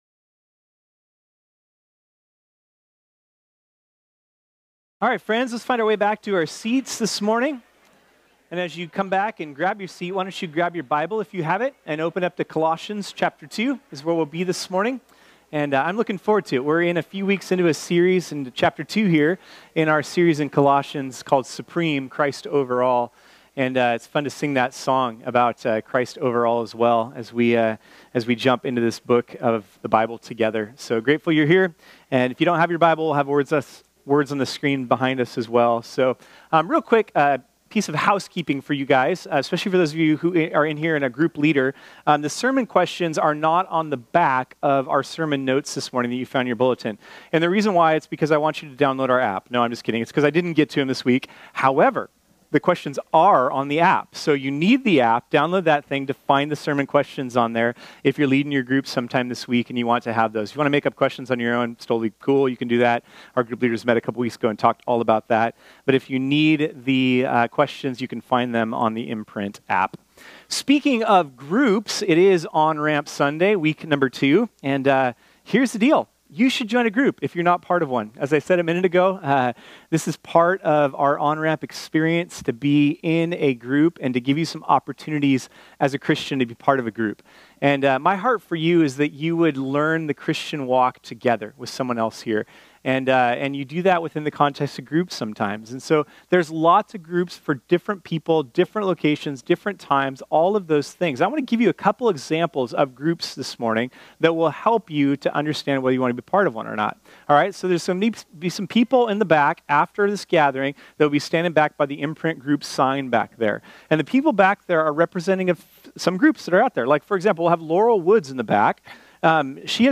This sermon was originally preached on Sunday, October 7, 2018.